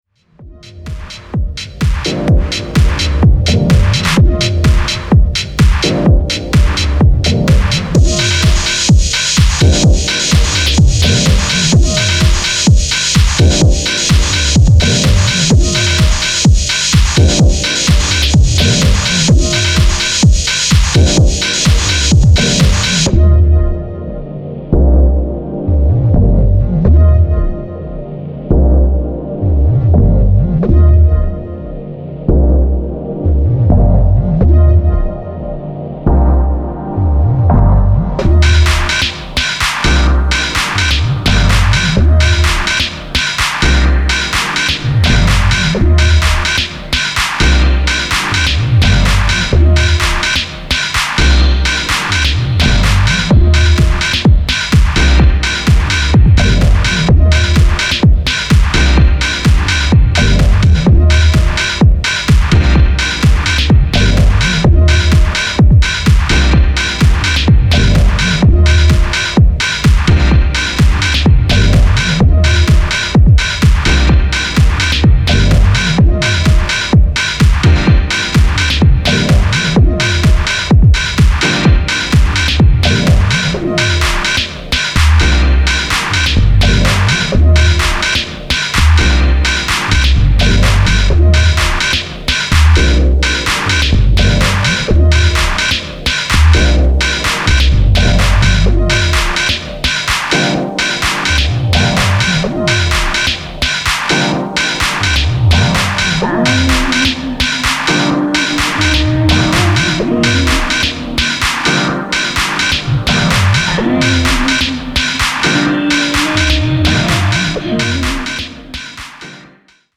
fresh faced club melancholy and West Coast synths